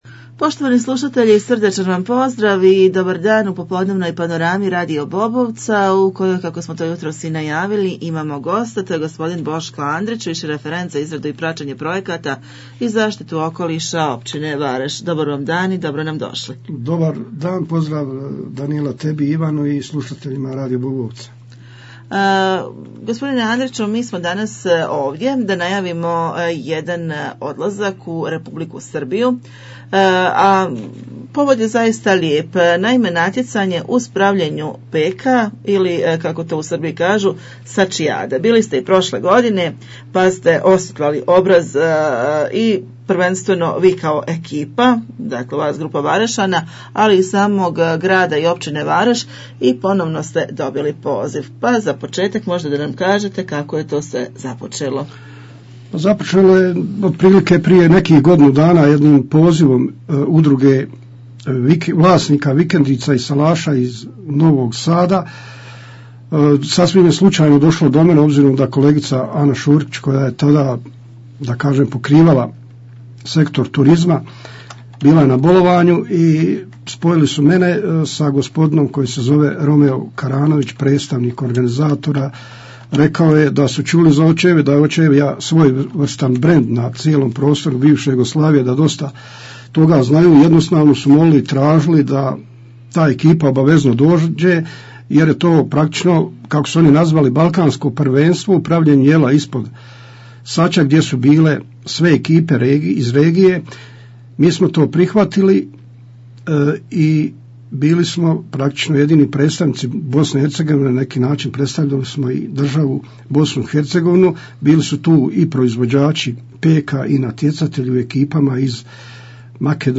"Saćijada" - razgovor